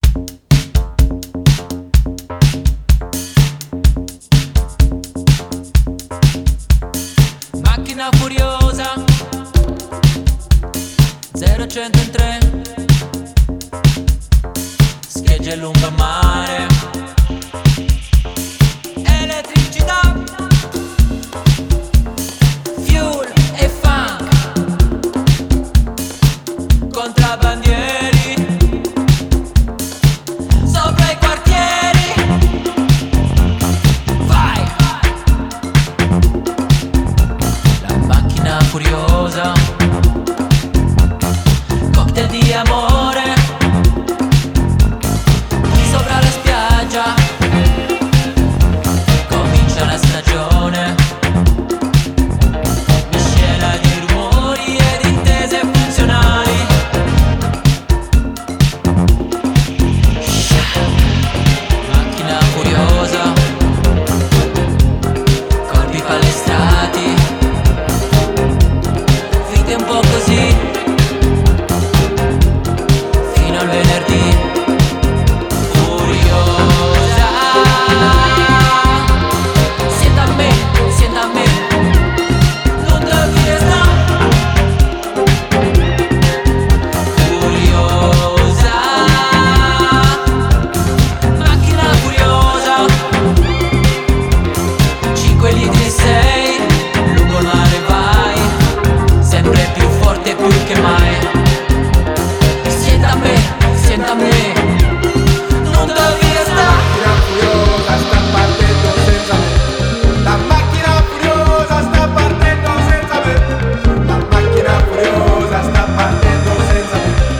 Boogie
Disco, Italo